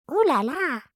Уляля жен